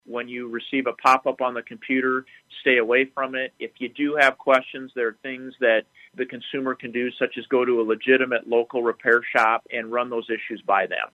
JACKLEY SAYS A REPUTABLE COMPUTER COMPANY WILL NEVER CONTACT A USER THROUGH A POP-UP MESSAGE: